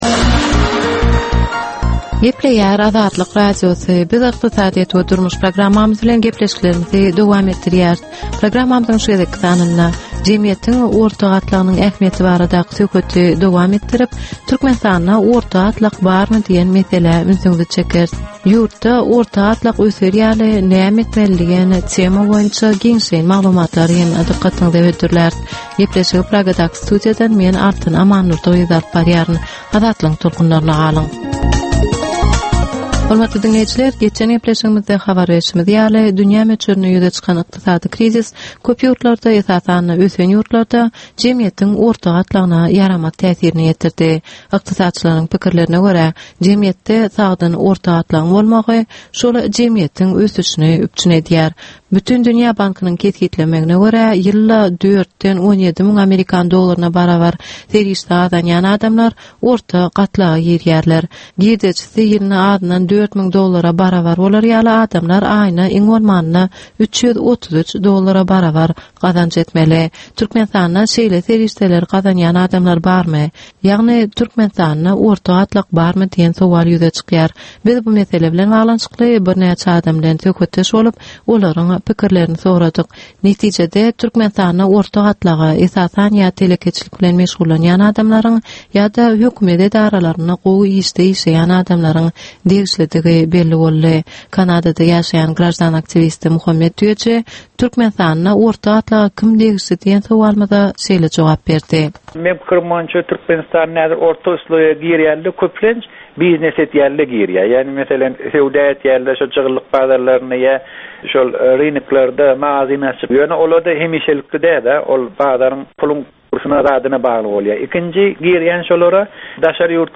Türkmenistanyn ykdysadyýeti bilen baglanysykly möhüm meselelere bagyslanylyp taýýarlanylýan 10 minutlyk ýörite geplesik. Bu geplesikde Türkmenistanyn ykdysadyýeti bilen baglanysykly, seýle hem dasary ýurtlaryñ tejribeleri bilen baglanysykly derwaýys meseleler boýnça dürli maglumatlar, synlar, adaty dinleýjilerin, synçylaryn we bilermenlerin pikirleri, teklipleri berilýär.